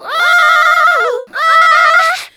SCREAM2   -R.wav